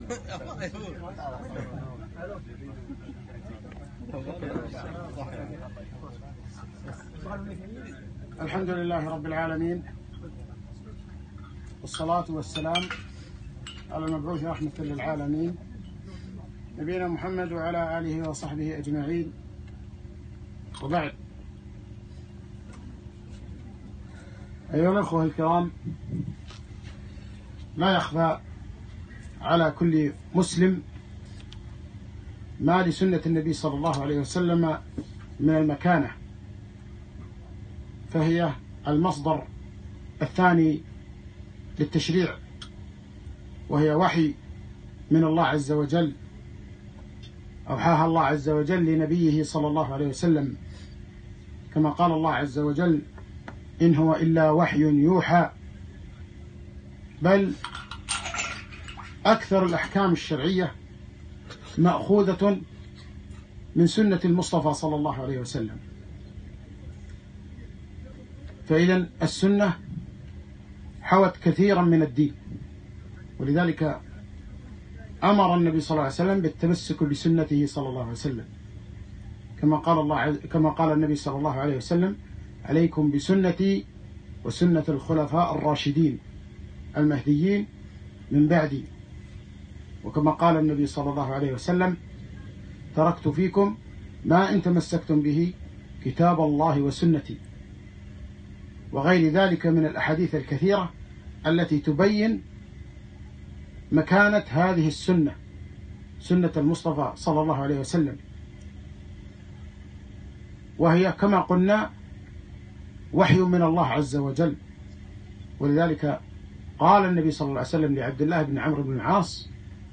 محاضرة - رواه الشيخان